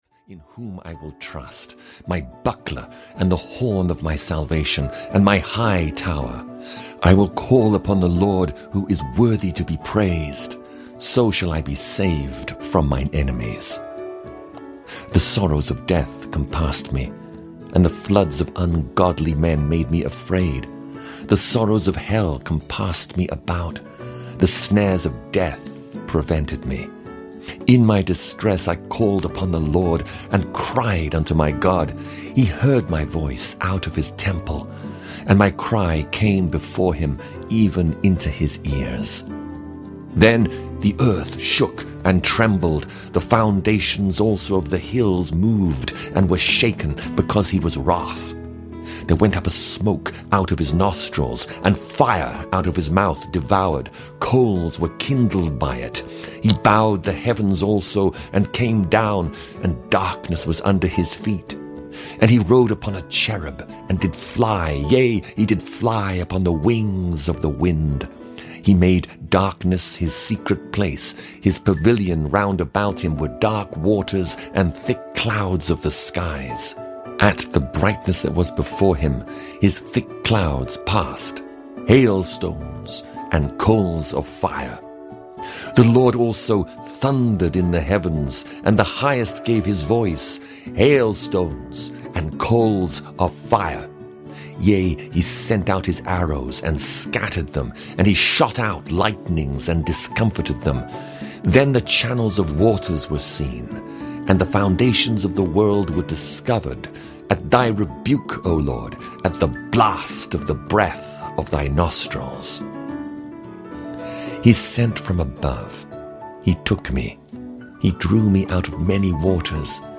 Audio knihaThe Old Testament 19 - Psalms (EN)
Ukázka z knihy